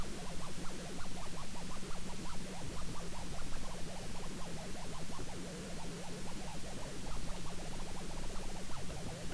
▶ Sound of MEMS with bit mismatch and unshielded cables, mono
Keep in mind that these recordings were made in quiet settings, and the volume is relatively high to make details more audible.
1_mems_mono_16bit_shield-not-grounded.wav